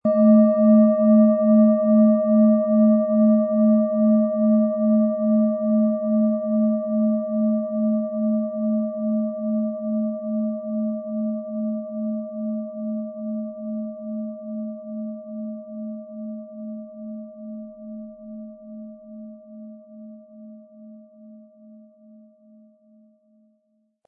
Planetenschale® Männlich und Weiblich ausgleichend & Ausgeglichen fühlen mit Eros, Ø 16,5 cm inkl. Klöppel
Aber dann würde der ungewöhnliche Ton und das einzigartige, bewegende Schwingen der traditionellen Herstellung fehlen.
MaterialBronze